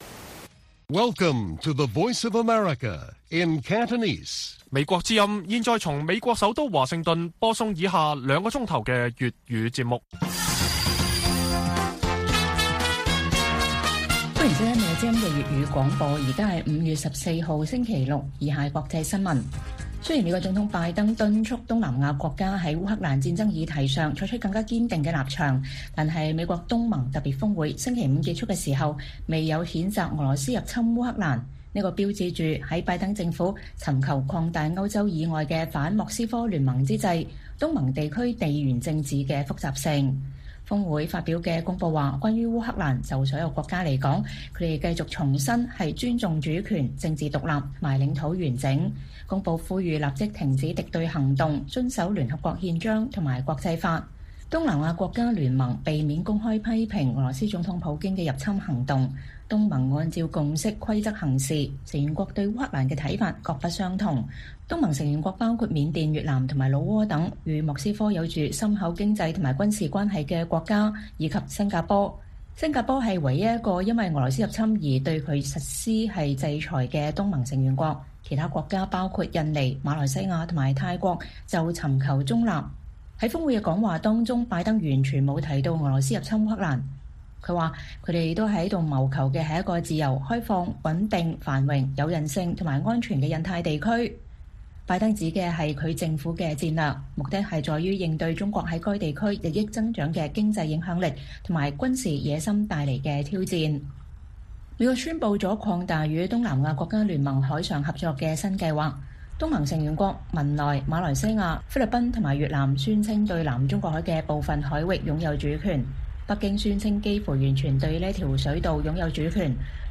粵語新聞 晚上9-10點: 美國-東盟峰會上沒有譴責俄羅斯